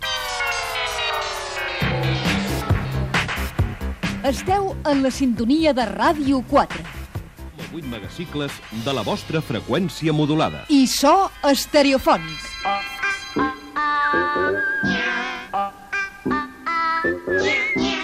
Indicatiu de l'emissora, amb so estereofònic